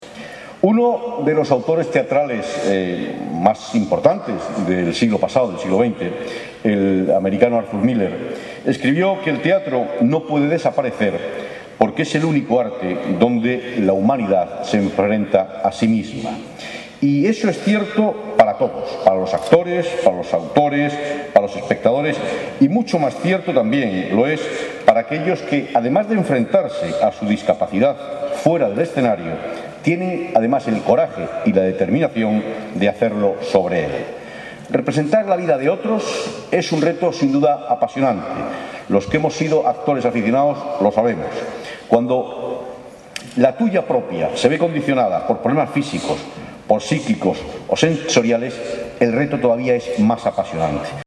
De esta manera, el alcalde de Almería, Luis Rogelio Rodríguez, daba la bienvenida a los participantes de la VI Muestra de Teatro Infantil y Juvenil ONCE durante su acto de inauguración, celebrado minutos antes de la primera de las representaciones, el pasado viernes 26 de septiembre,